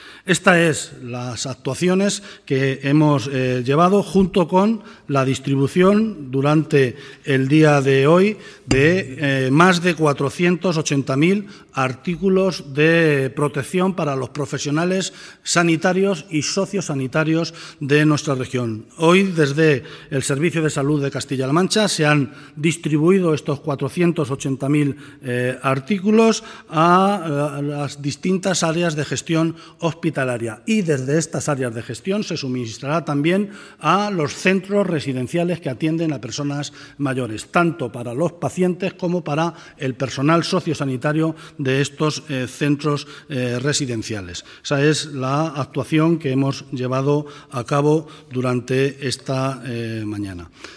• Comparece el vicepresidente del Gobierno regional, José Luis Martínez Guijarro, para informar de  la reunión del Consejo de Gobierno de Castilla-La Mancha
En rueda de prensa tras celebrar el Consejo de Gobierno de la región, Martínez Guijarro ha apuntado que, si a nivel nacional se pone en marcha un sistema orientado a esta garantía de ingresos, las comunidades autónomas y entidades locales podrían complementarla.